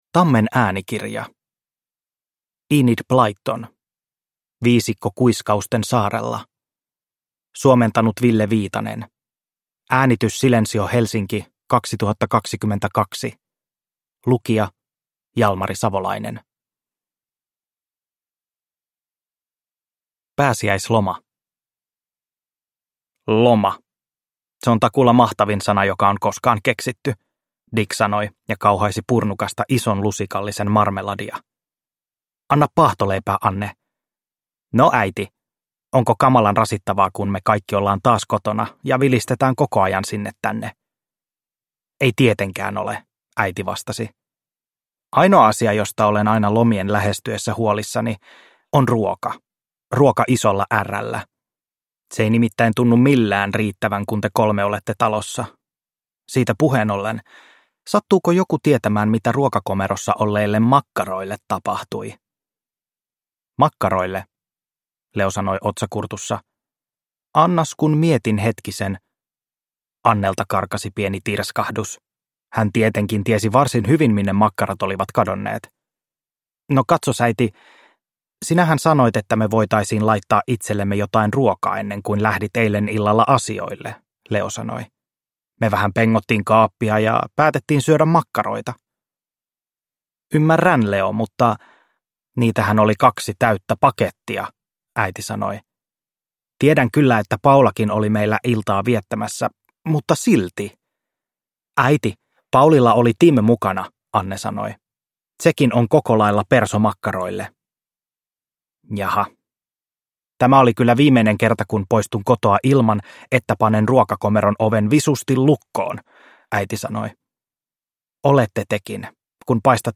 Viisikko Kuiskausten saarella – Ljudbok – Laddas ner